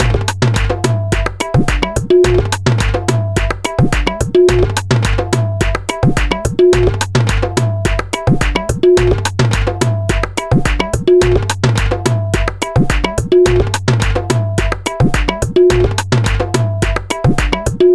108个疯狂的Moombahcore阿尔文风险像第一部分
描述：节奏为108，所以它更适合Moombahton/dubstep。
Tag: 108 bpm Dubstep Loops Bass Loops 1.50 MB wav Key : Unknown